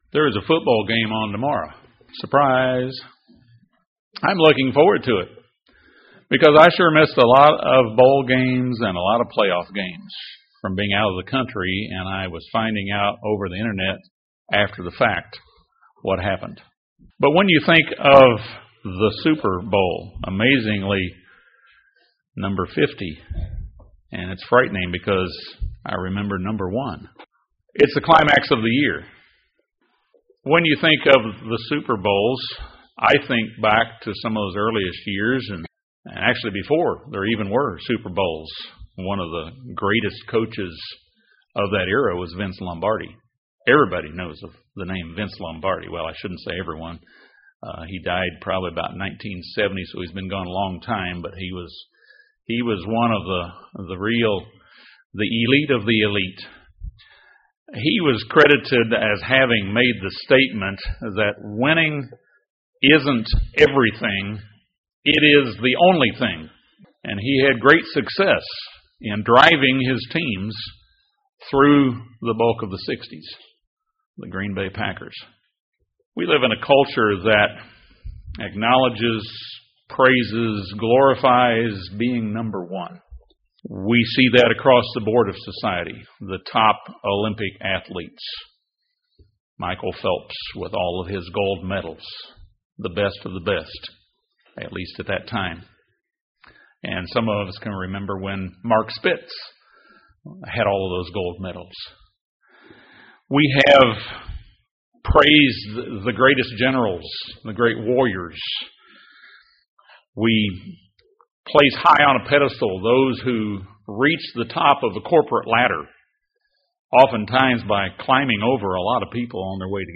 This sermon discusses the tendency of human nature to strive to be number 1.